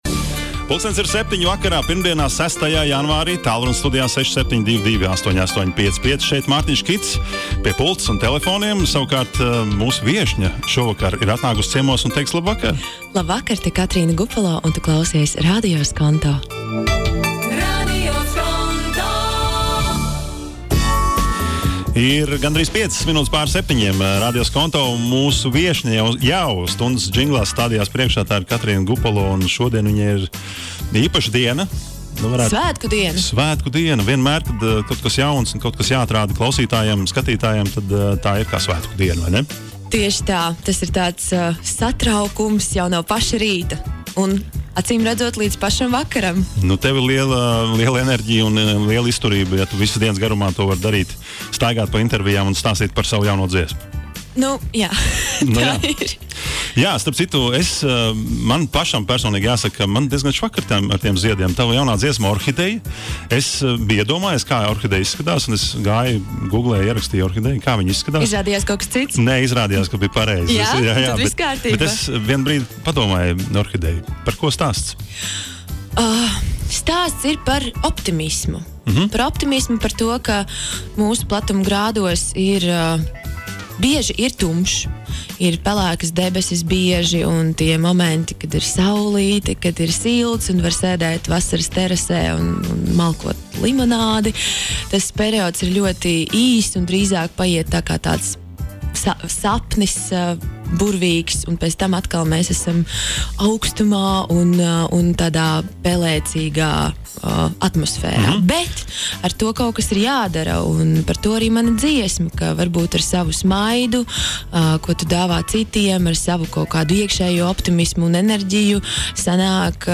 INTERVIJAS